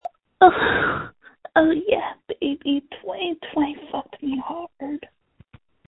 • When you call, we record you making sounds. Hopefully screaming.
• This website is an archive of the recordings we received from hundreds of thousands of callers.